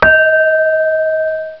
chime.sound